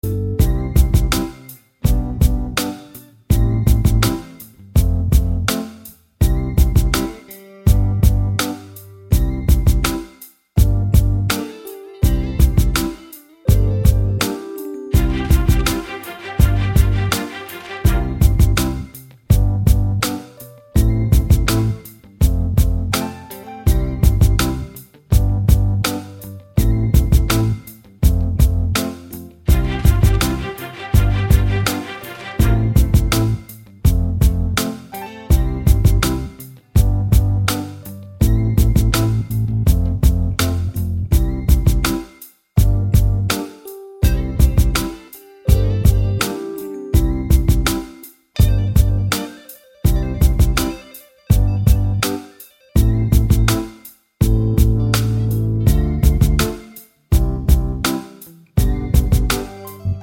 no Backing Vocals R'n'B / Hip Hop 4:20 Buy £1.50